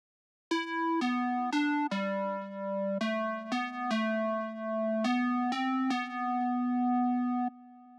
34 Square Synth PT2.wav